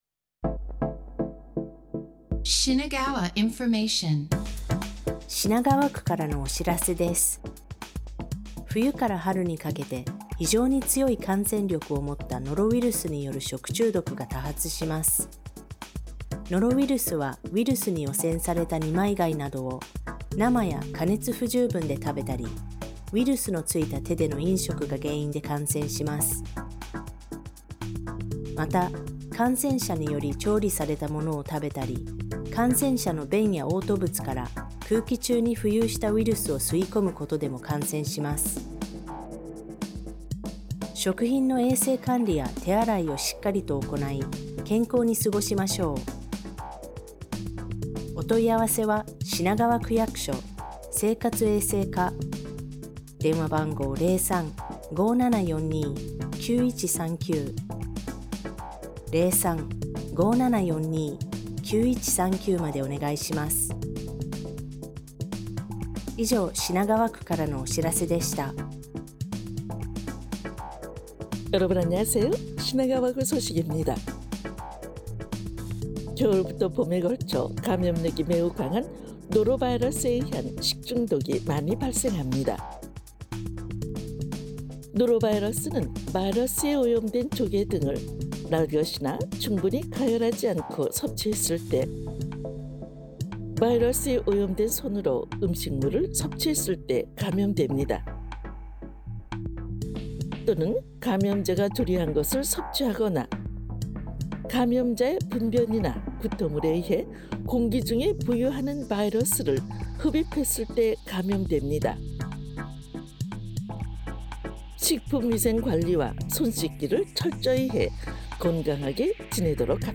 (Audio) 2026년 1월 13일 방송 ‘겨울철 식중독 주의하세요’